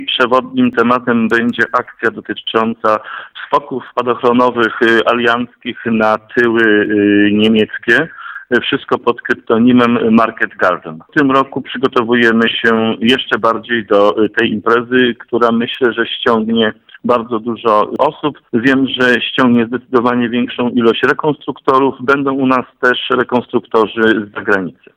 Co tym razem jest hasłem przewodnim mówił w magazynie Gość Dnia Radia Żnin FM burmistrz Łabiszyna Jacek Idzi Kaczmarek.